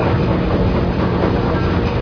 techage_oildrill.ogg